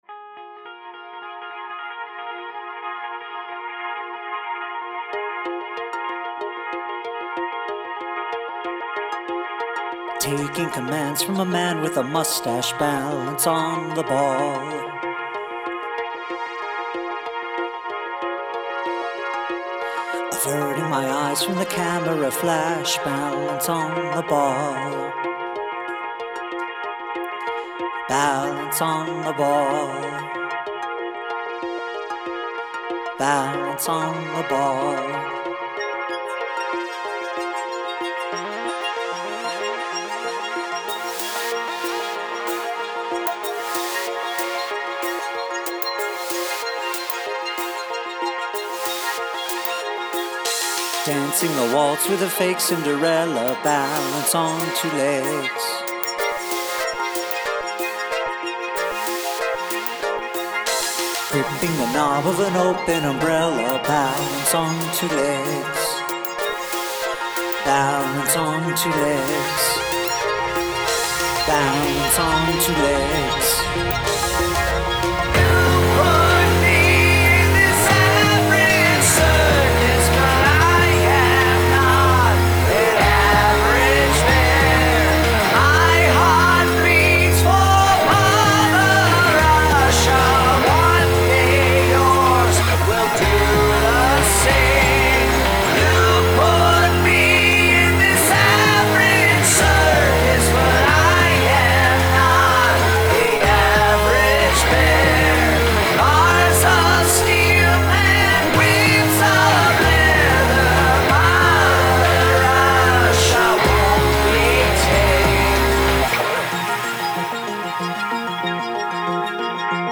Use something from your garbage bin as an instrument
I love the synth sounds.